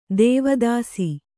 ♪ dēva dāsi